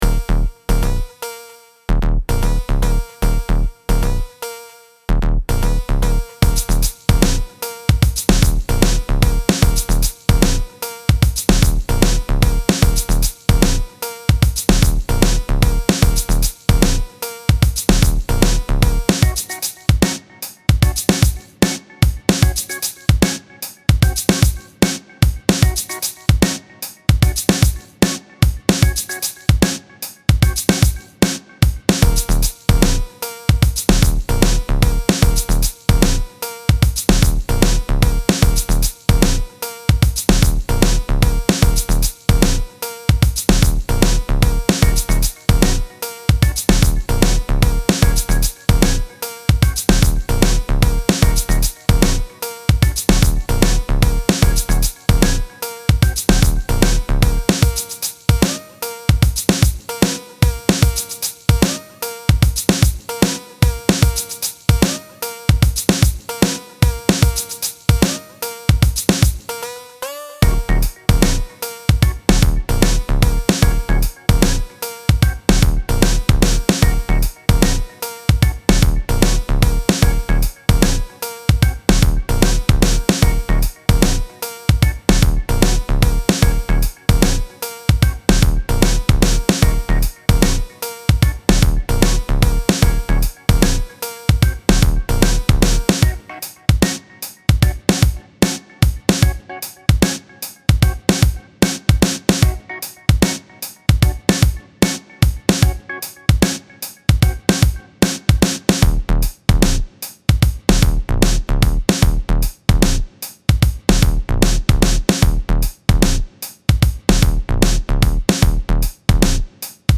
Style Style Hip-Hop, Pop
Mood Mood Cool, Relaxed
Featured Featured Bass, Drums, Organ +1 more
BPM BPM 75